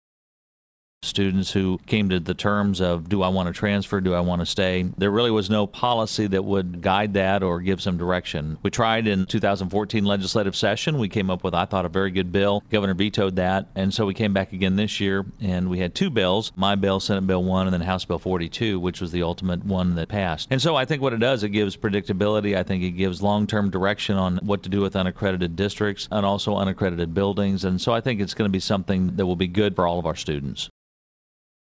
The following audio comes from the above interview with Sen. Pearce, for the week of May 4, 2015.